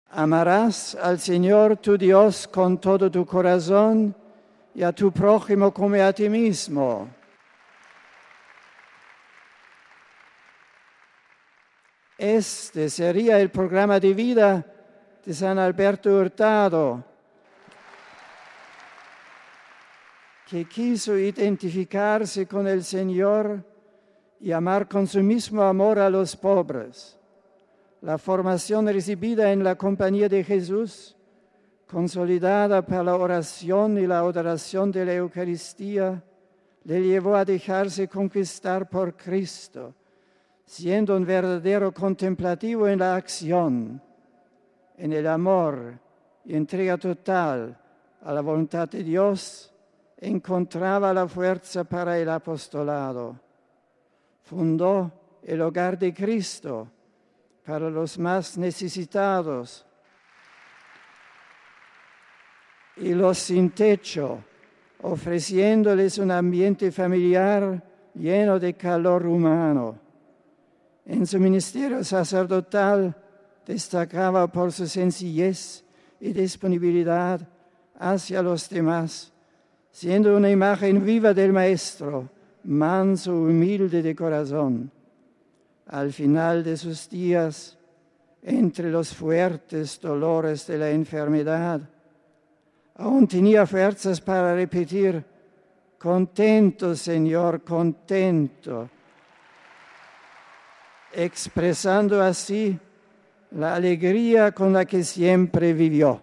Escuchemos las palabras de Benedicto XVI en su homilía, destacando el carisma de San Alberto Hurtado: RealAudio